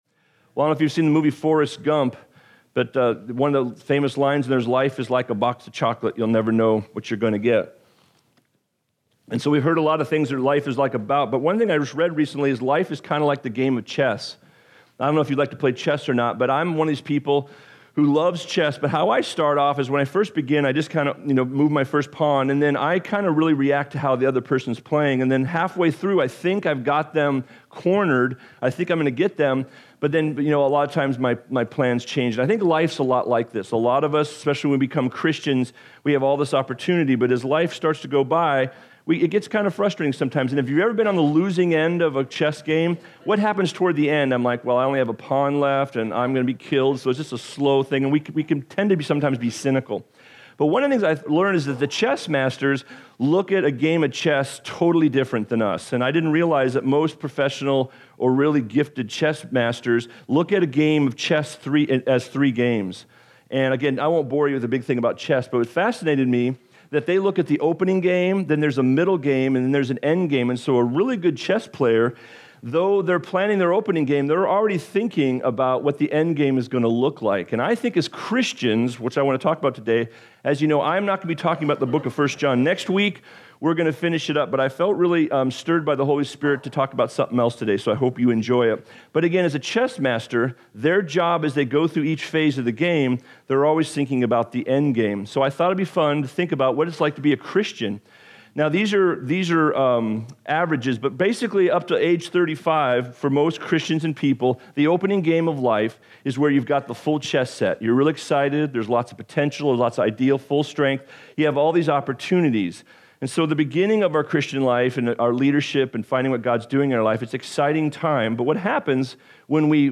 Years of trials sometimes results in a worn out believer who does not pursue God, but coasts away from an effective ministry and lifestyle. I this Sermon we investigate what scripture says about aging well, drawing closer to God, and encouraging others around you.